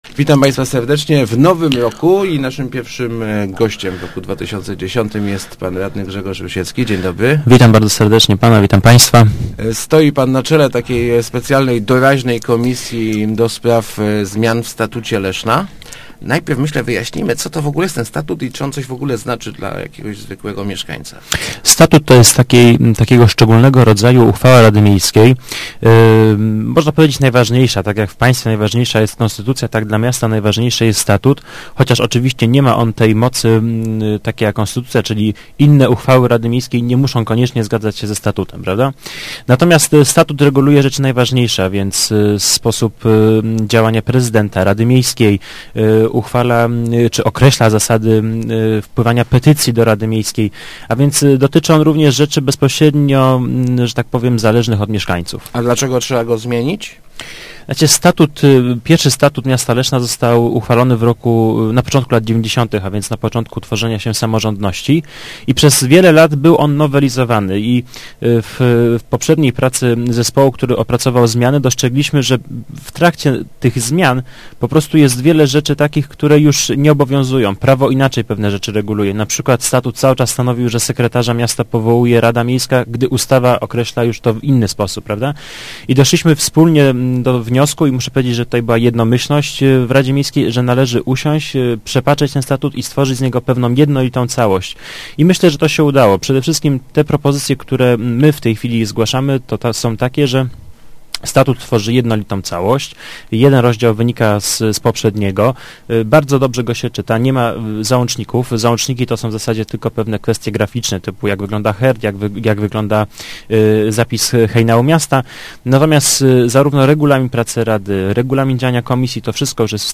Mówił o nim w Rozmowach Elki radny Grzegorz Rusiecki stojący na czele komisji do spraw opracowania dokumentu. Nowy statut ma też wprowadzić prawo wywieszania flagi miasta przez każdego mieszkańca.